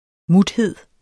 Udtale [ ˈmudˌhedˀ ]